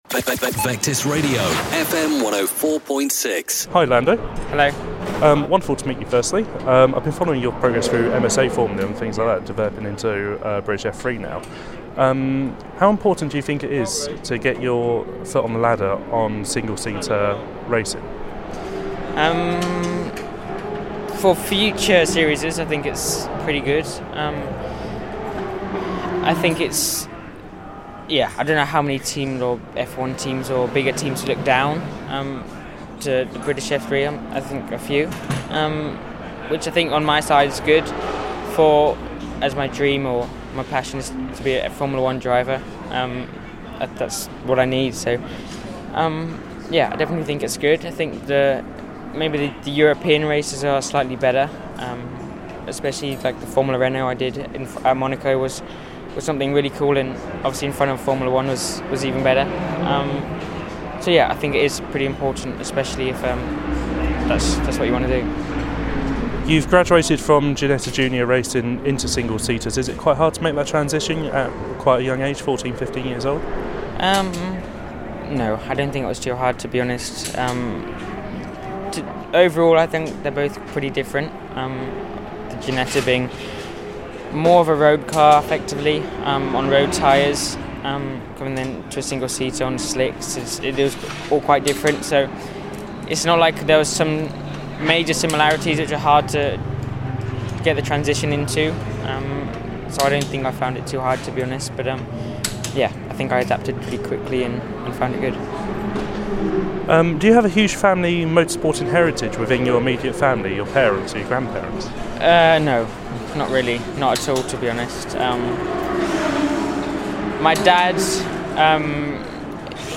Never broadcast since, here is that interview.